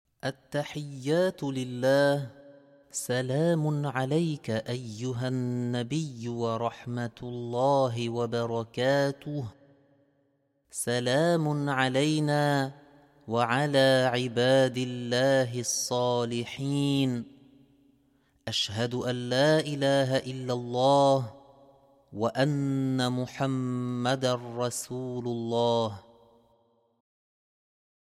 minimum-tachahhoud.mp3